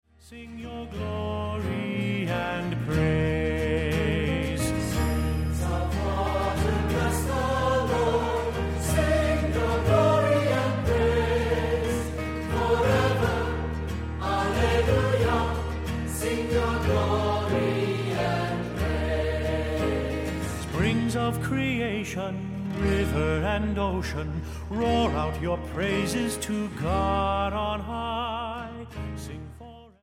Voicing: Unison; SATB; Cantor